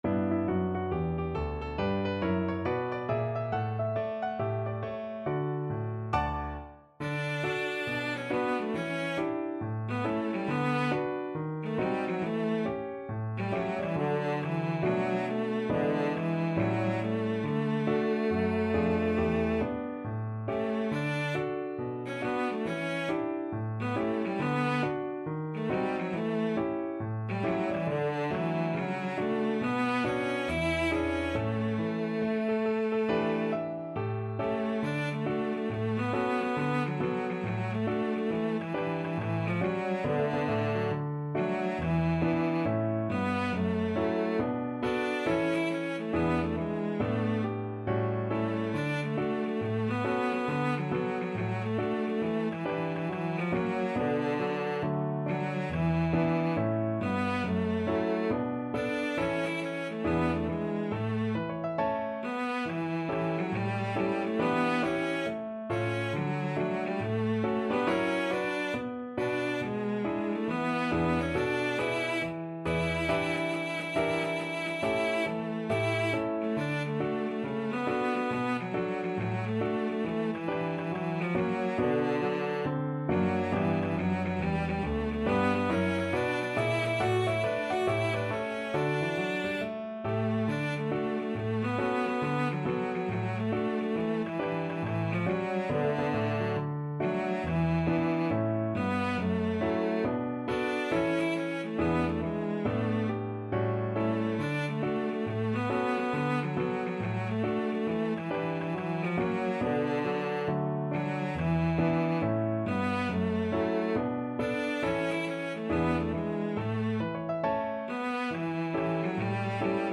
With a swing =c.69
4/4 (View more 4/4 Music)
Pop (View more Pop Cello Music)